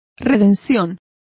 Complete with pronunciation of the translation of redemption.